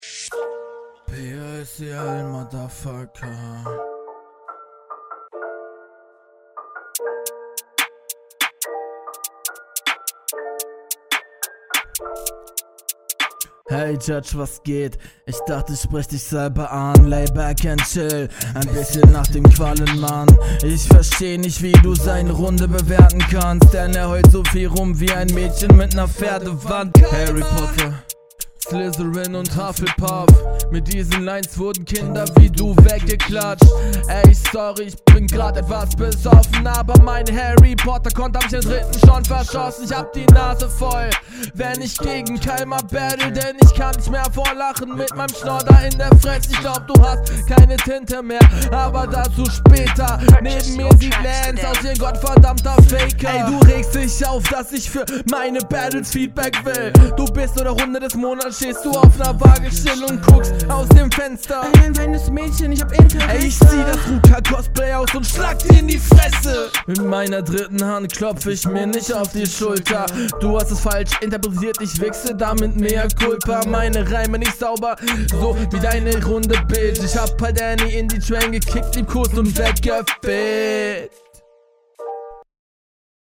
Der Stimmeinsatz ist okay, die Delivery auch, der Takt wird überwiegend getroffen, aber die Variationen …
Du kommst auf den Beat nicht klar.